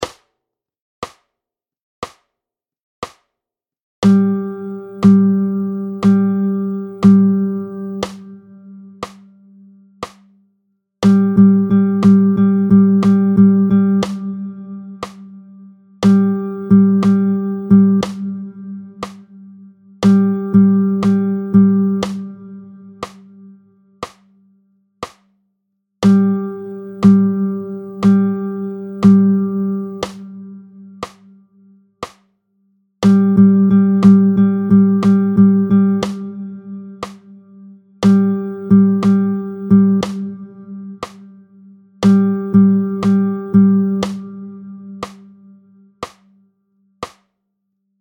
22-02 Les rythmes ternaires, tempo 60